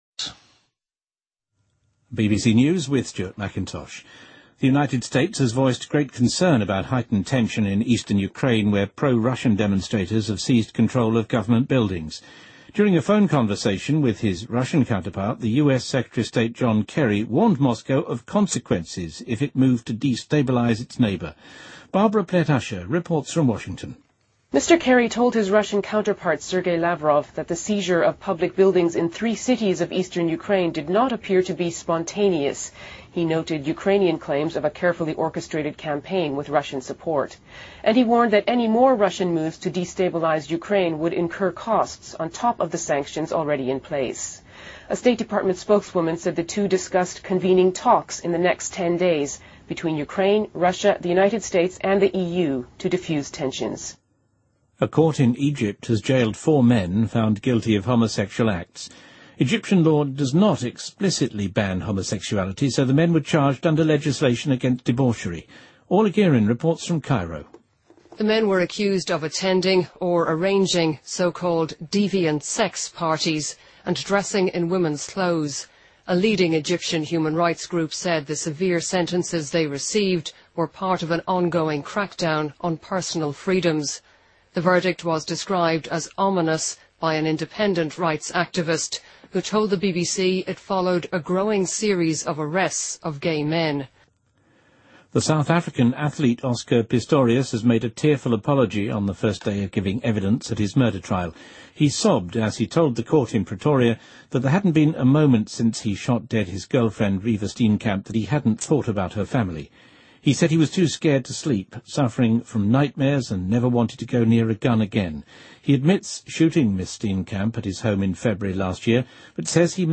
BBC news,2014-04-08